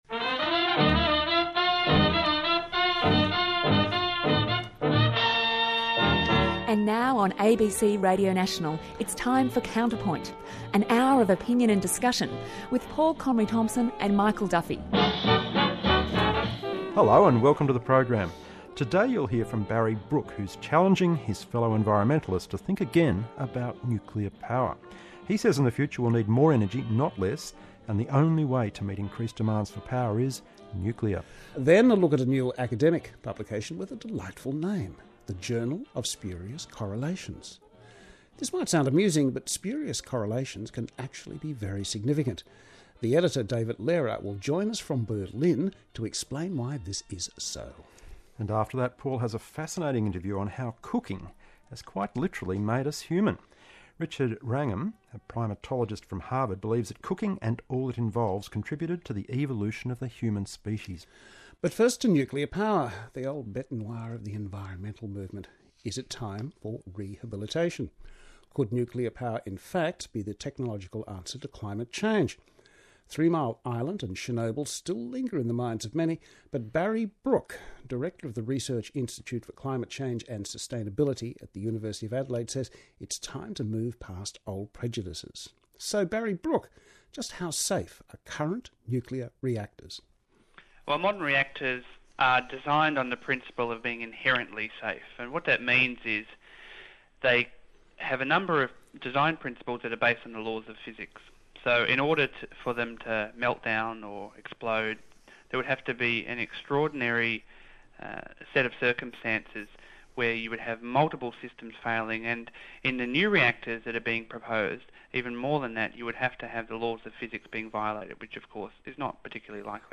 I’d be interested in any feedback you, as BNC readers, have — not only on the content of the interview (remember, this was done off the top of my head, so there may be a few minor misstatements), but also on the effectiveness (or not) of this sort of communication strategy.